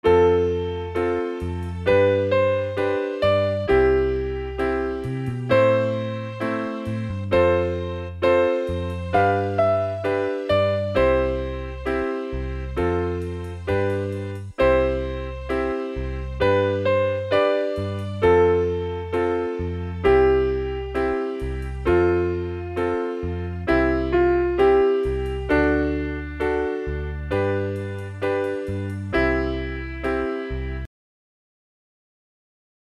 Slow Tempo